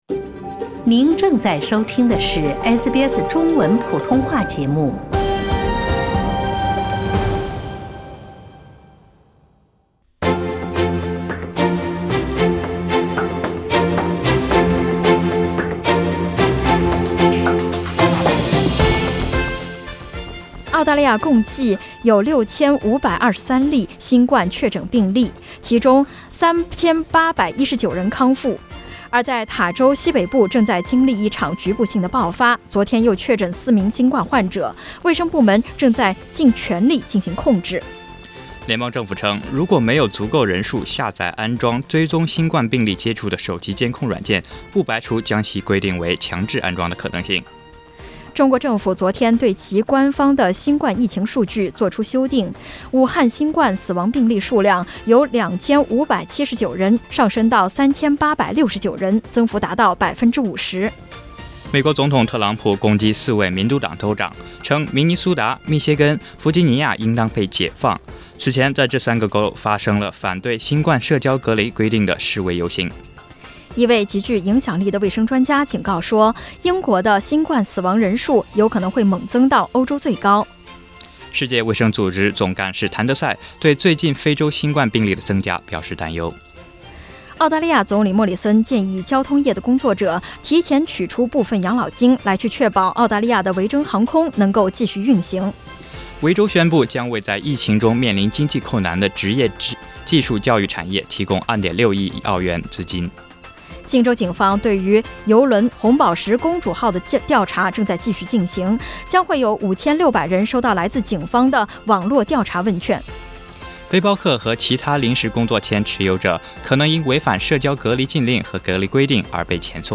SBS早新闻（4月18日）